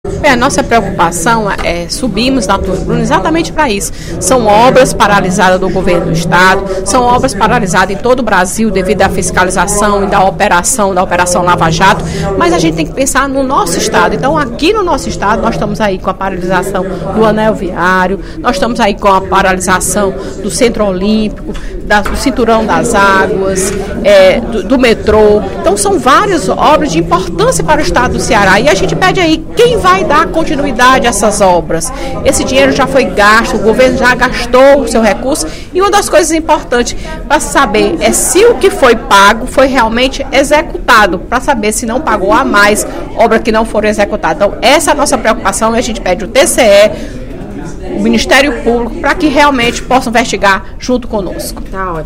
Em pronunciamento no primeiro expediente da sessão plenária da Assembleia Legislativa desta quinta-feira (09/04), a deputada Fernanda Pessoa (PR) destacou a demissão dos trabalhadores de obras públicas, como o Anel Viário de Fortaleza e o Centro de Formação Olímpica, ambas de responsabilidade da empresa Galvão Engenharia.